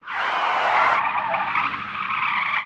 weave.ogg